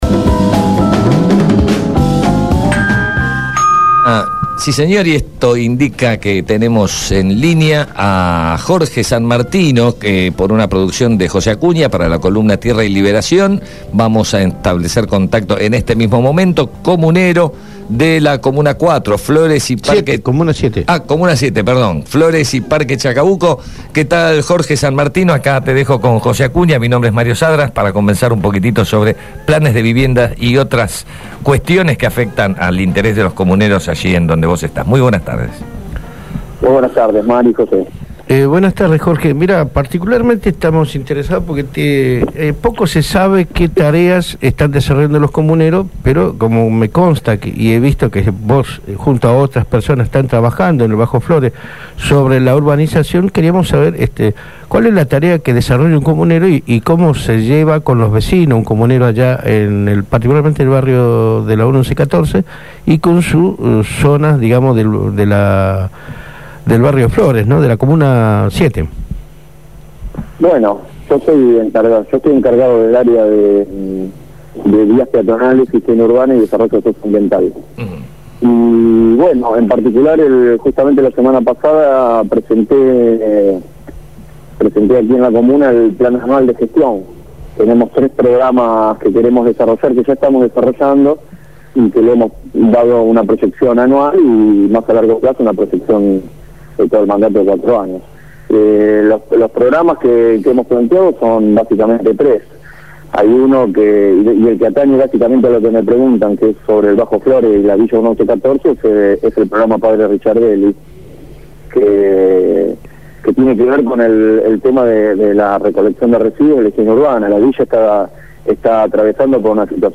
Jorge Sanmartino, comunero por Proyecto Sur en la comuna 7, habló en Abramos la Boca.